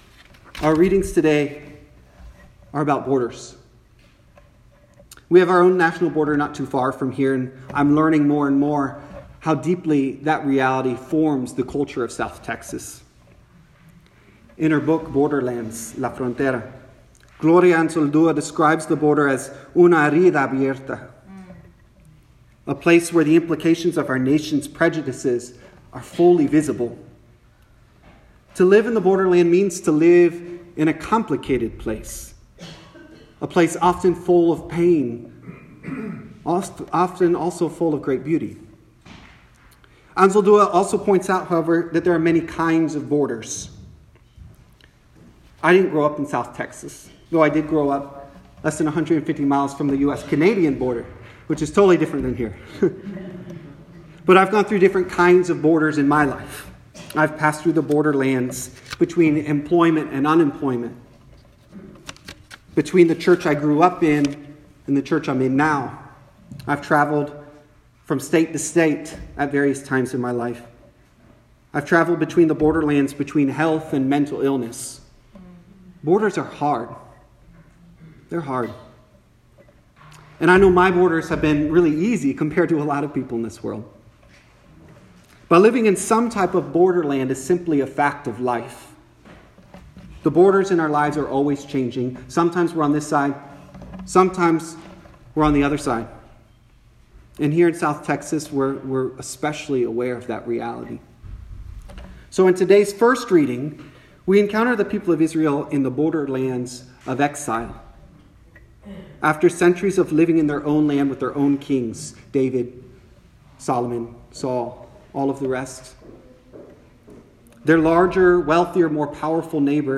santa-fe-episcopal-church-4.m4a